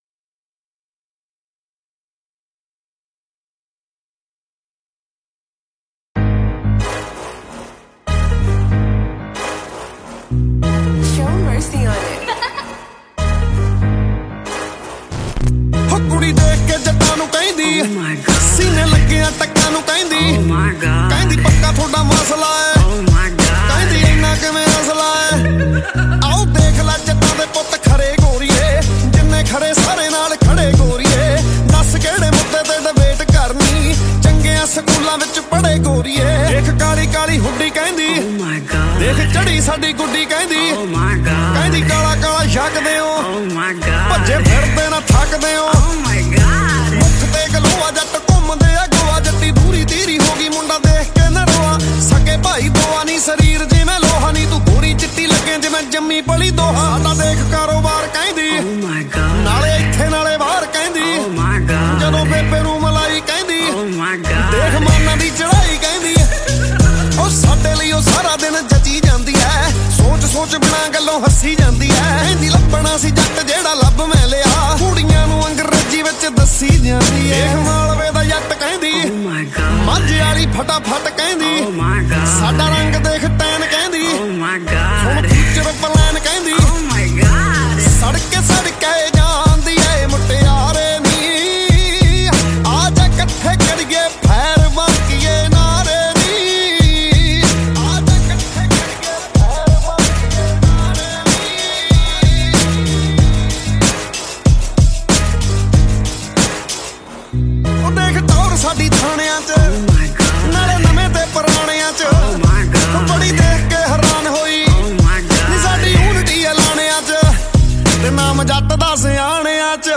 New Punjabi Song 2023